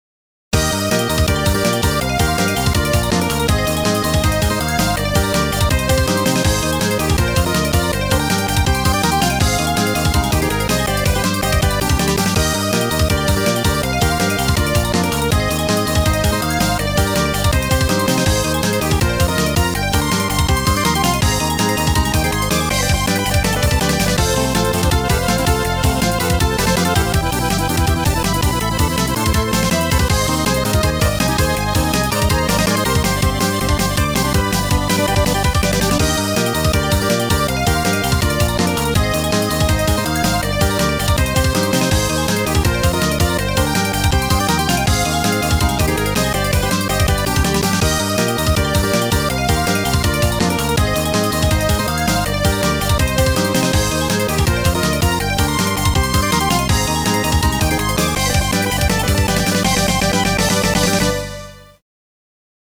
曲エディット目当てで買ったのですが、スーファミくさい音色が素敵です。
特にストリングス系の何とも言えない響きが懐かしい。
折角なので、試しに自作アレンジをバンブラに移植してみました。
いかにも呑んべぇパワーって感じです。